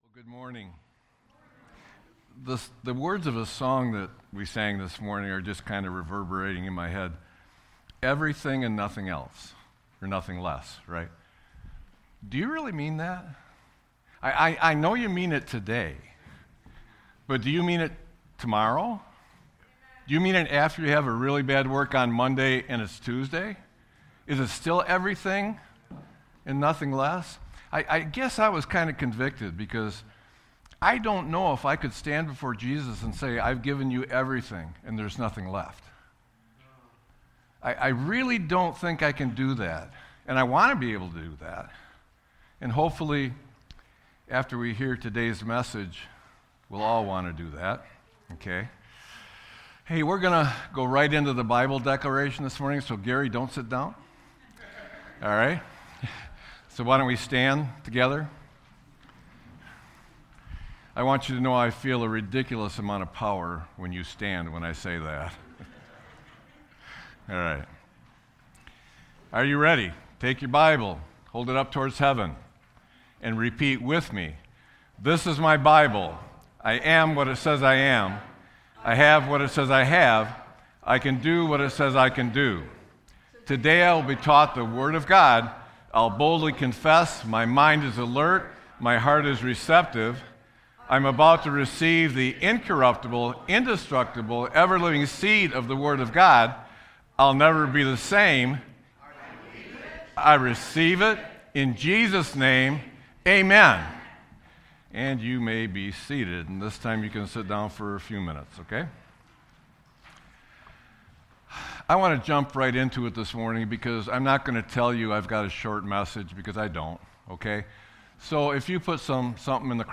Sermon-4-12-26.mp3